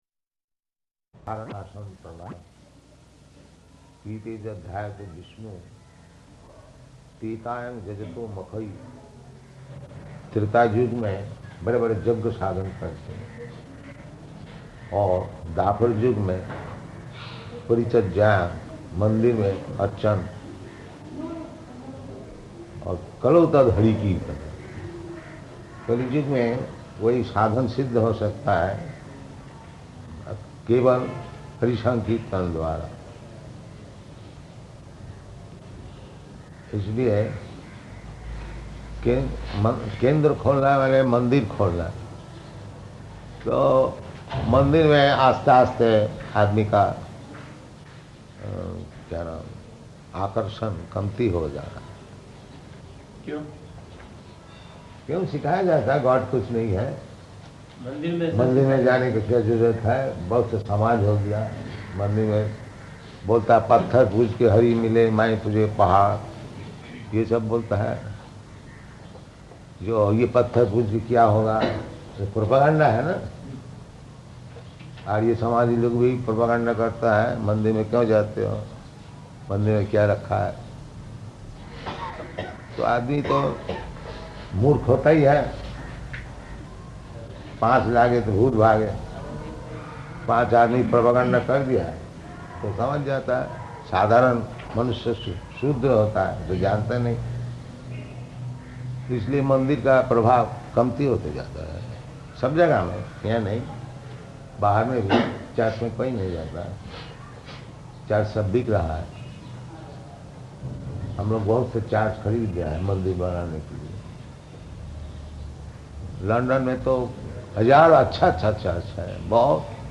Room Conversation in Hindi
Type: Conversation
Location: Ahmedabad